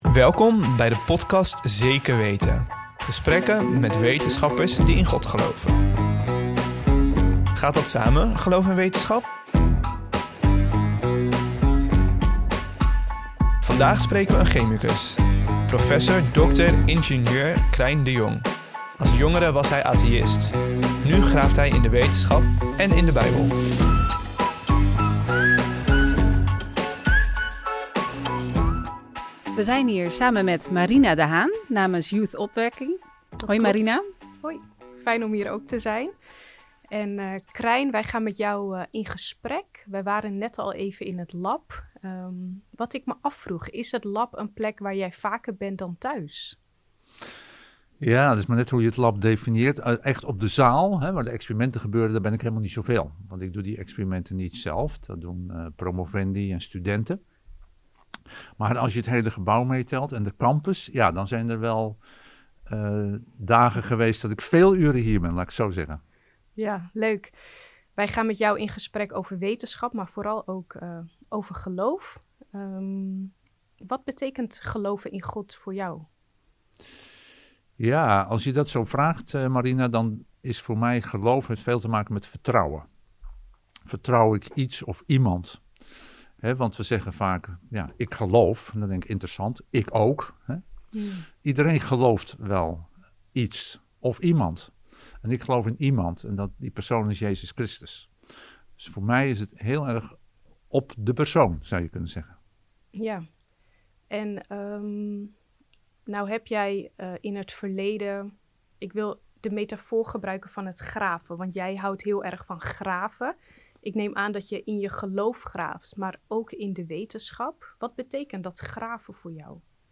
Welkom bij de podcast Zeker Weten, gesprekken met wetenschappers die in God geloven. Gaat dat samen, geloof en wetenschap?